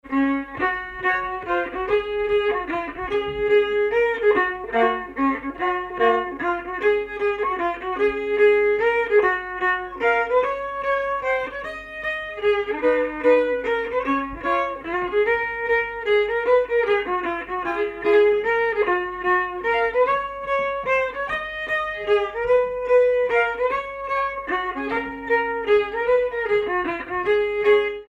Danse
circonstance : bal, dancerie
Pièce musicale inédite